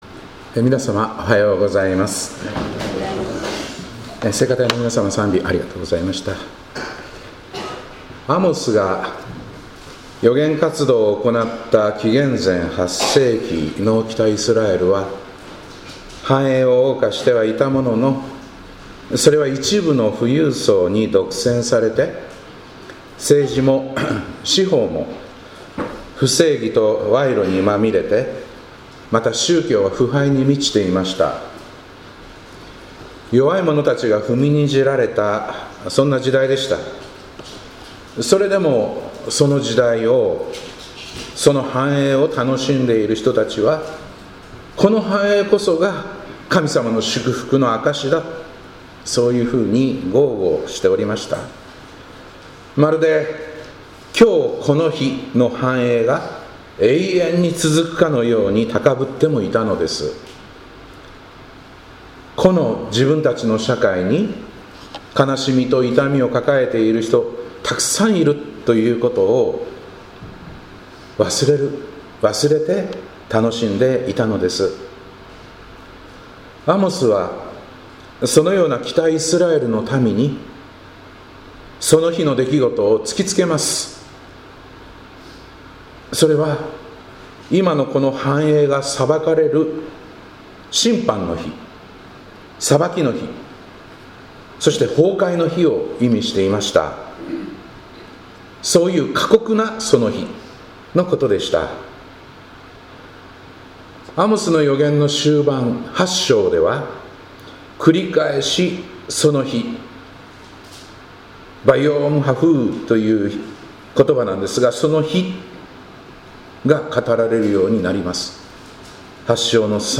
2025年11月16日礼拝「その日とこの日」